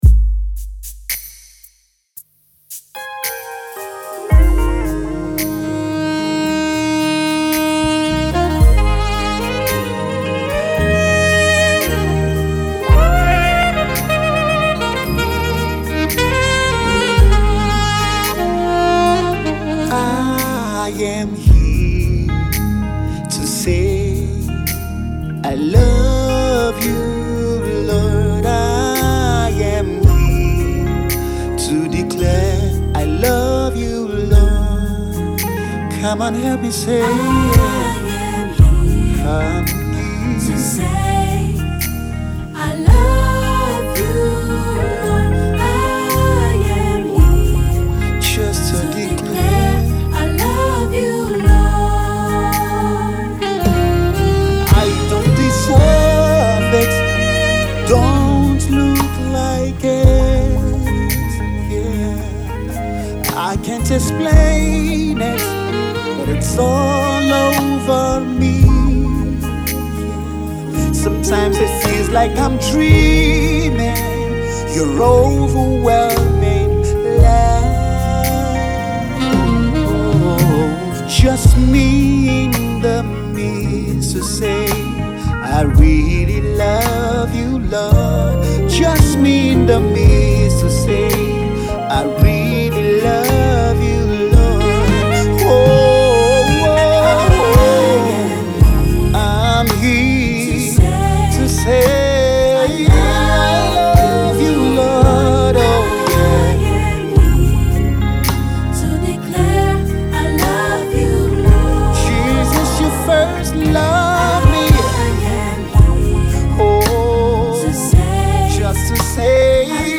a simple, heartfelt worship song